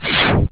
MiniSpeak Special FX Sound Files (Full Version)
zoomingby.wav